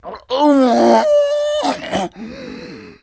STRANGLED
PAINHANG13.WAV